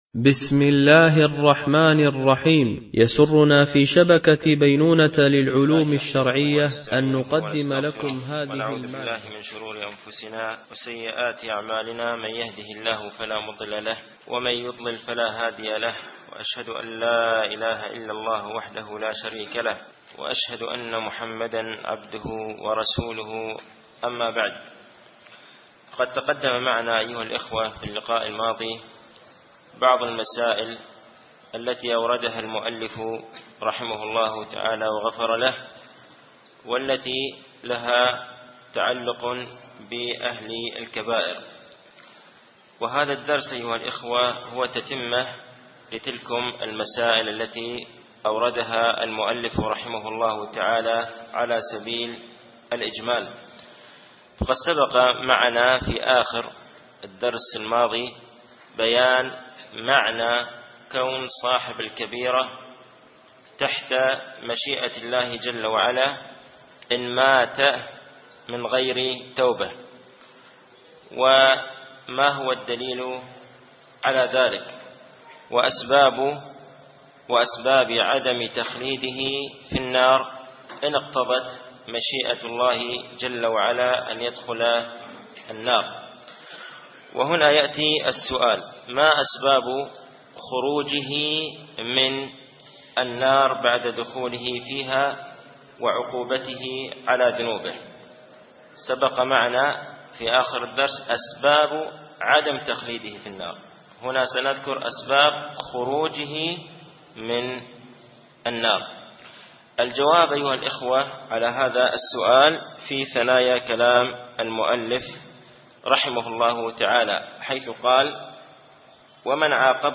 شرح مقدمة ابن أبي زيد القيرواني ـ الدرس الثامن و الثلاثون